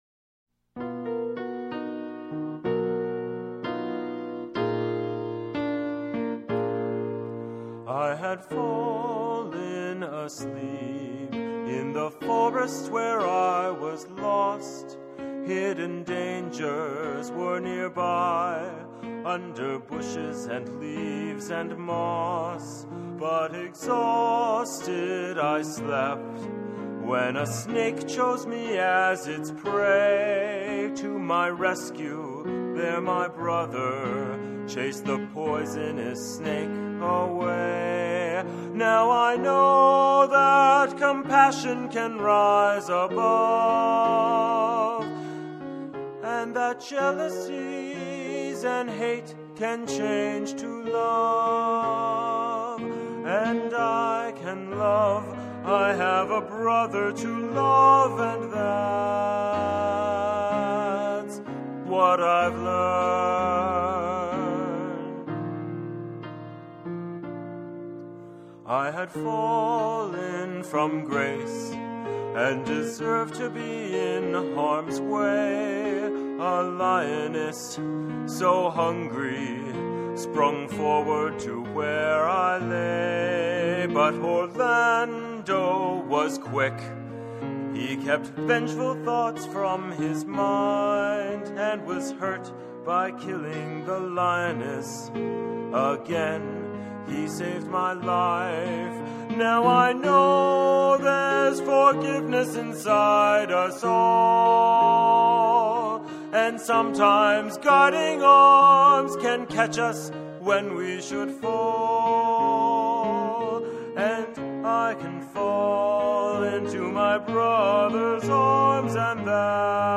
Oliver's song of repentence